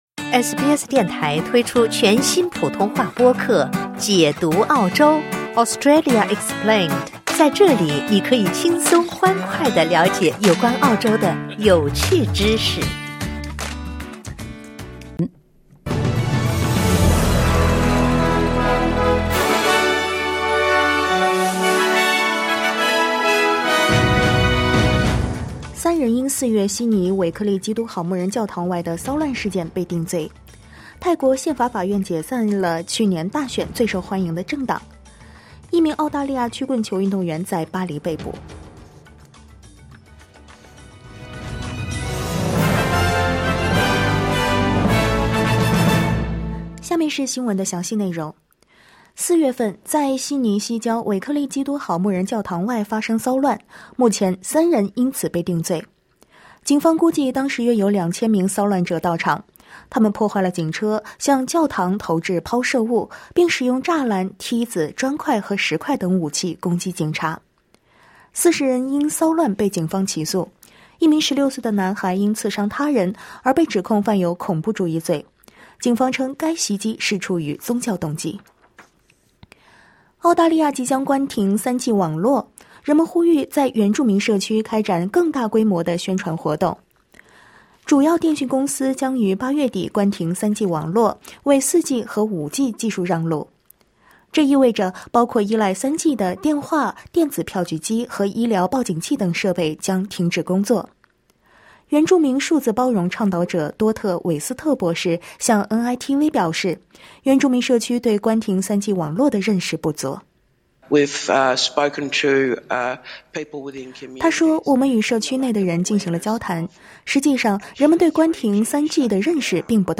SBS Mandarin morning news.